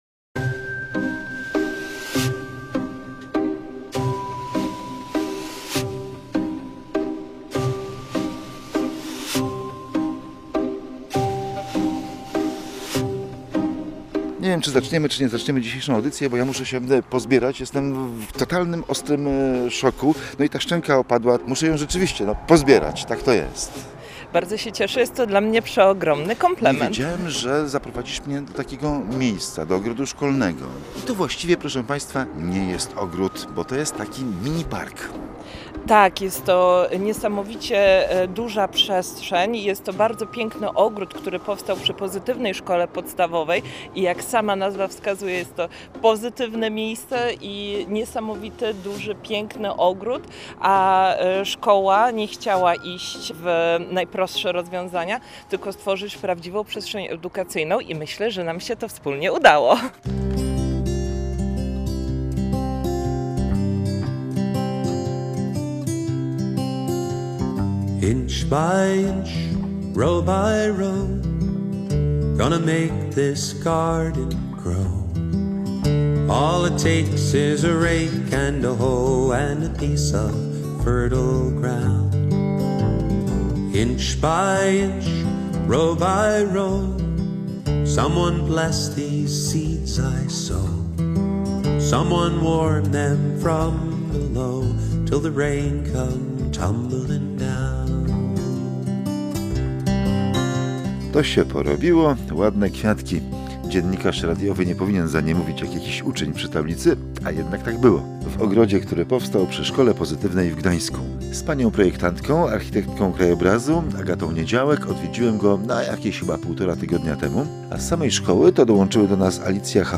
Po drodze spotkaliśmy dzieci, które przyszły, żeby zobaczyć, jak rosną zasadzone przez nie bratki i nauczycieli, opowiadających o ogrodowych lekcjach przyrodniczych i rodzinnych warsztatach ekologicznych.
W ogrodzie spotkaliśmy grupę uczniów, którzy sprawdzali, jak rosną ich kwiatki.